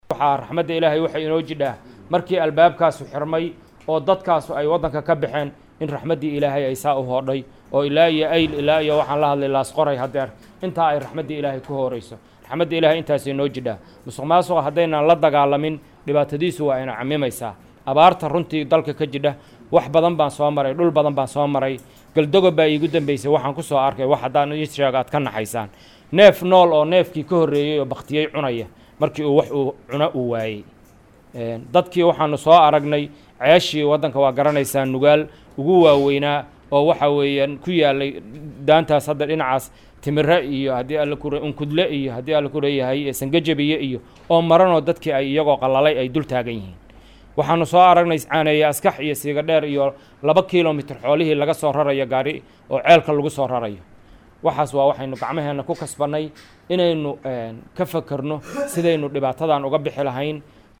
December 20 2016 (Puntloandes)-Gudoomiyihii hore ee gobolka Mudug Maxamed Yuusuf Jaamac Tigey oo hadda ka shaqeeya arrimaha bulshada, islamarkaana kuhowlanaa gurmadka abaaraha ayaa ka sheekeeyey wixii uu kusoo arkay deegaamo badan oo uu booqday.